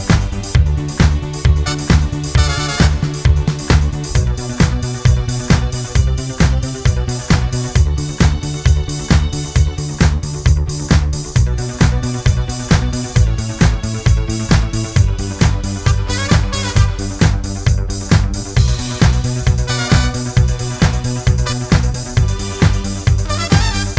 no Backing Vocals Disco 3:04 Buy £1.50